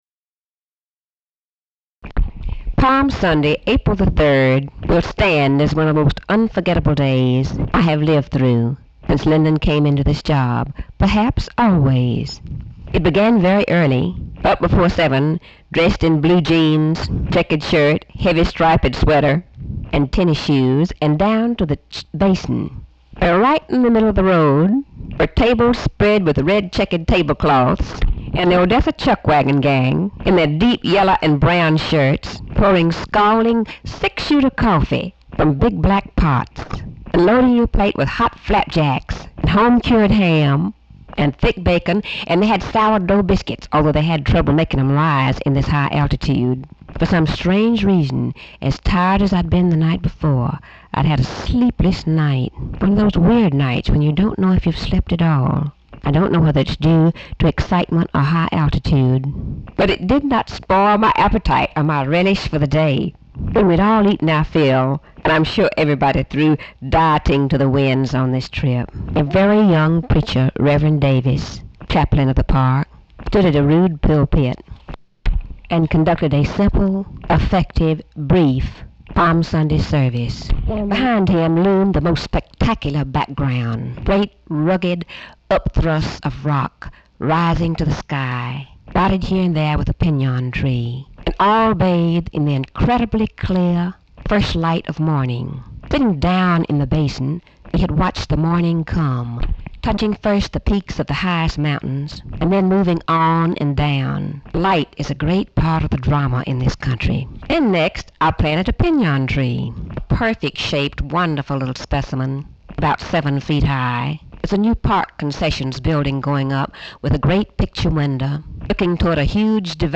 Audio diary and annotated transcript, Lady Bird Johnson, 4/3/1966 (Sunday) | Discover LBJ